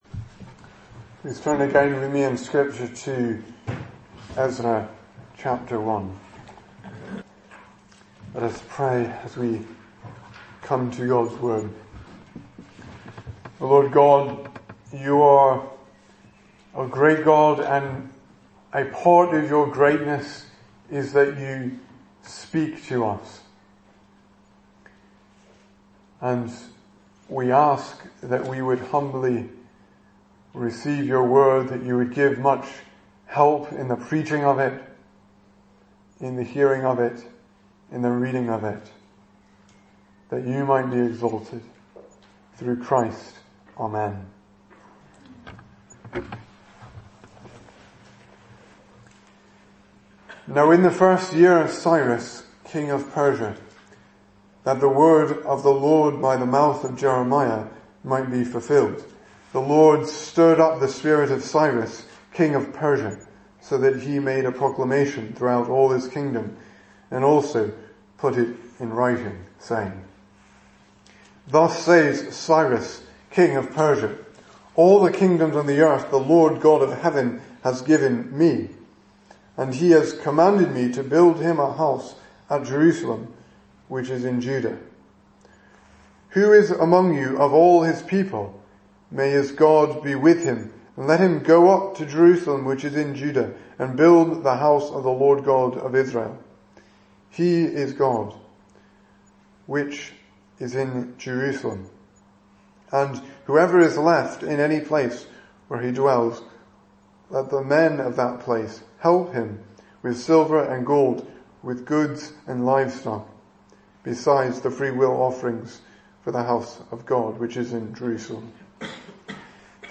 2018 Service Type: Sunday Evening Speaker